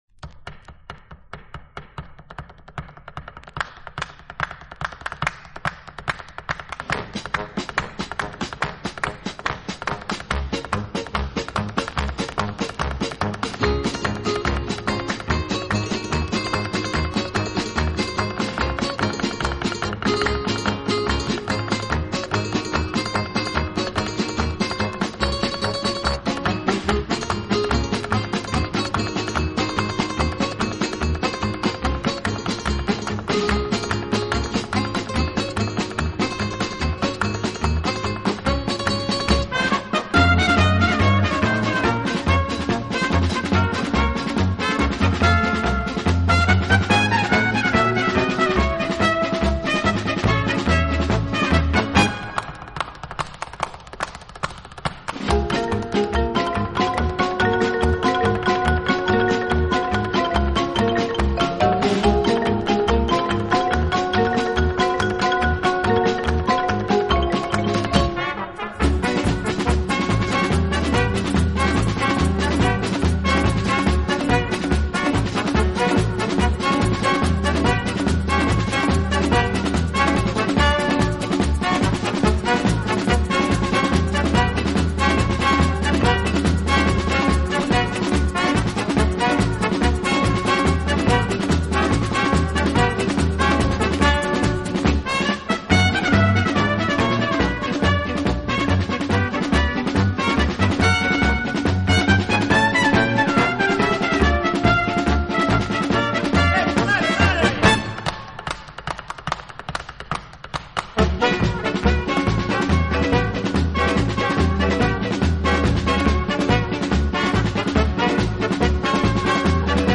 演奏轻柔优美，特別是打击乐器的演奏，具有拉美音乐独特的韵味。